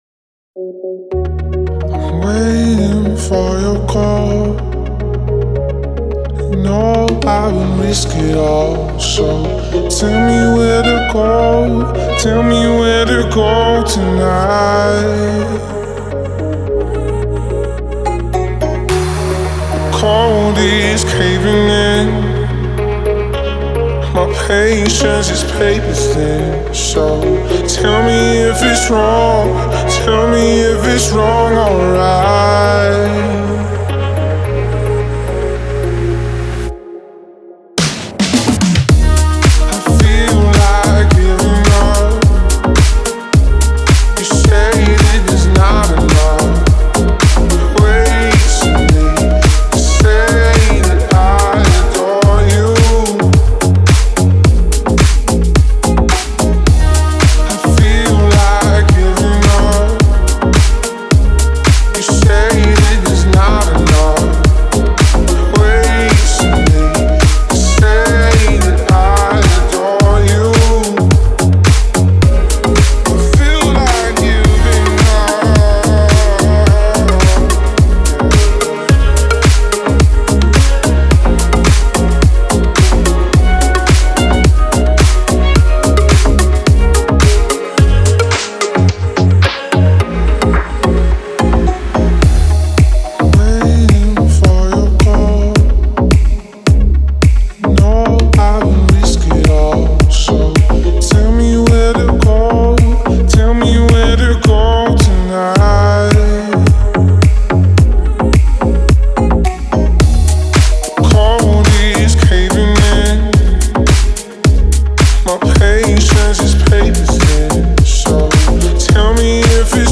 это захватывающая композиция в жанре техно